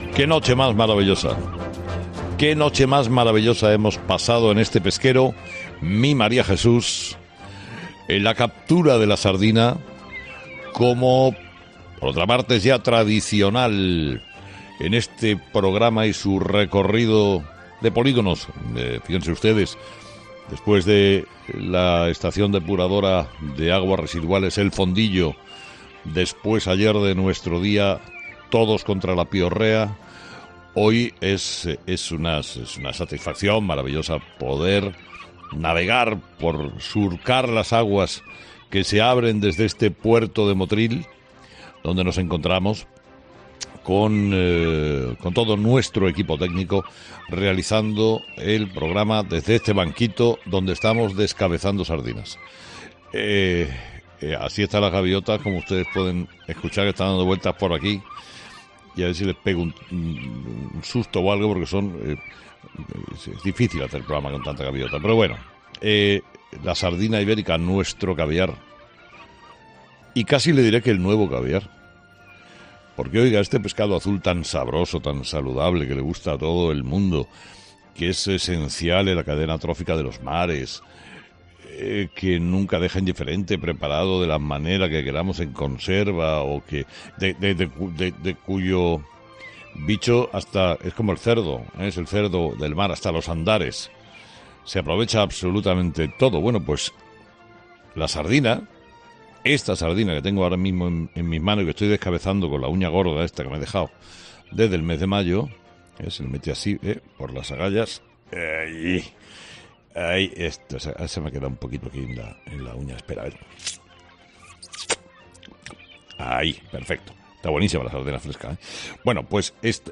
El comunicador se embarca en el pesquero "Mi Maria Jesús" para hablar sobre este "nuevo caviar"